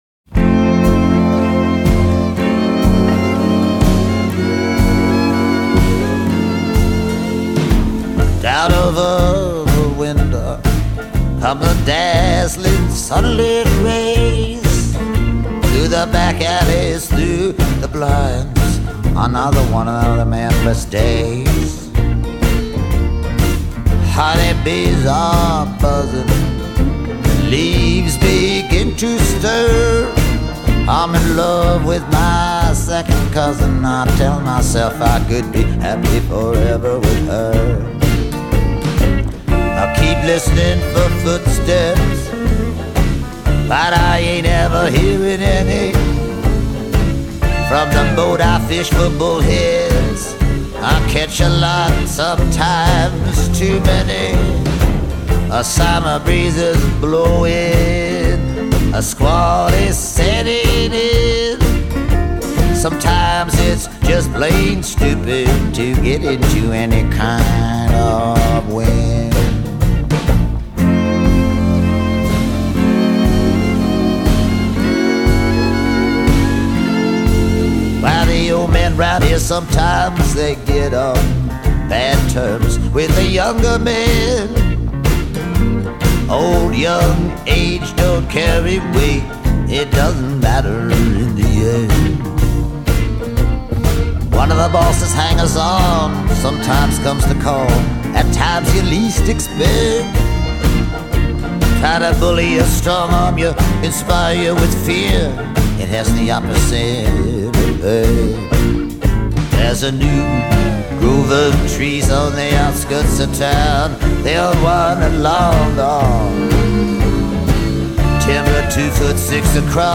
The instrumental breaks are a nice touch